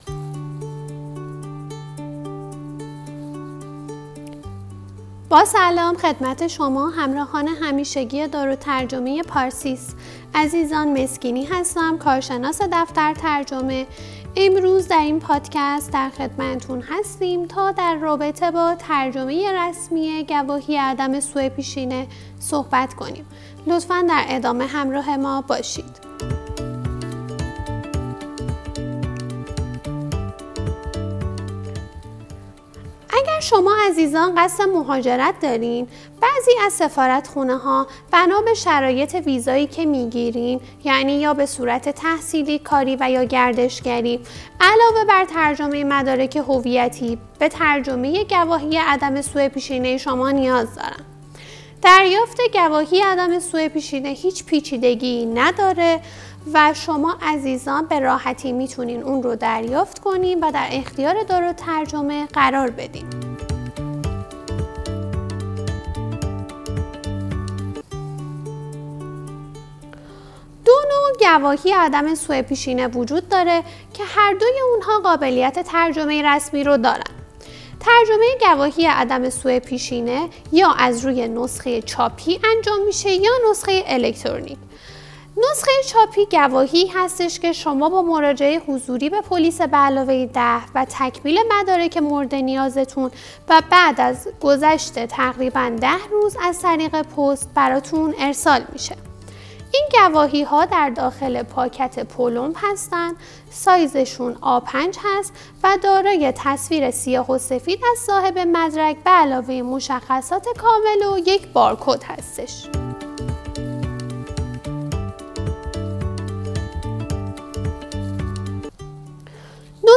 در پادکست زیر کارشناس ترجمه ما در خصوص نحوه ترجمه از روی گواهی اعم از روش اینترنتی و نسخه چاپی و تفاوت آن را توضیح خواهند داد.